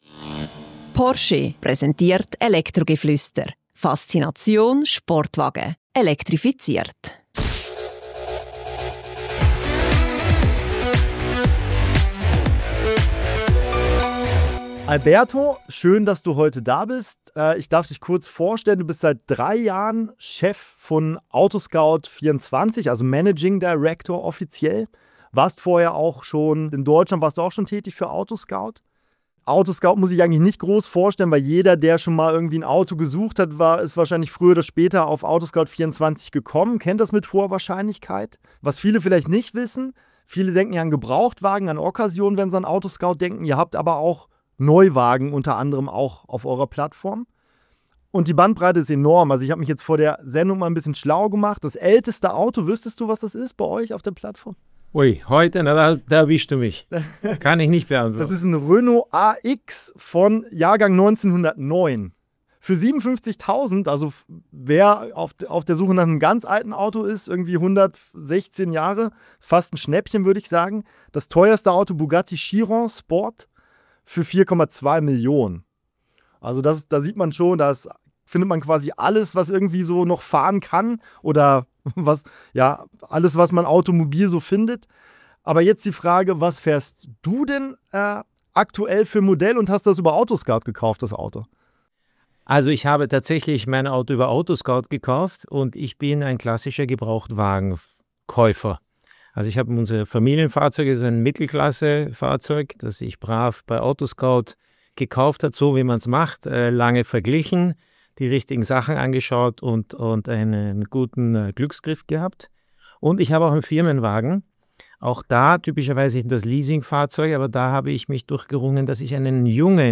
Während der Markt wächst, sinken die Preise weiter. Im Gespräch mit dem Moderationsduo